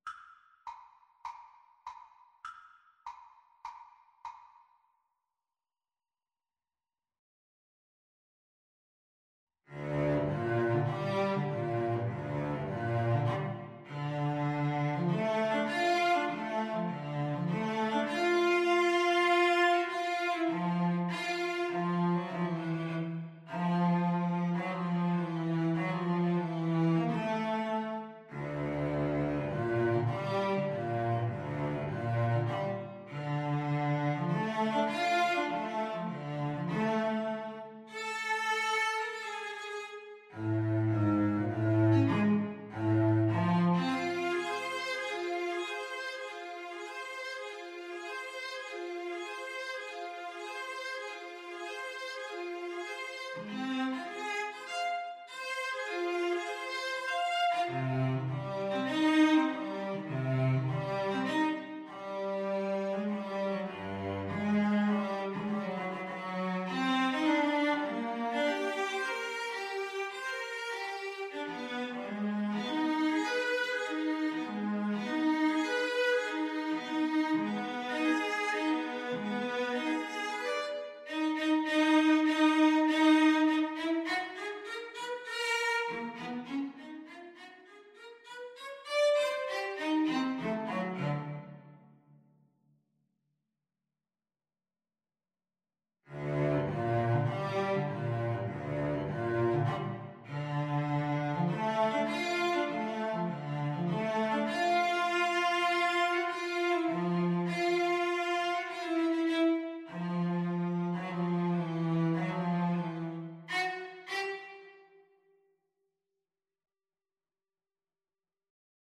Cello Duet  (View more Advanced Cello Duet Music)
Classical (View more Classical Cello Duet Music)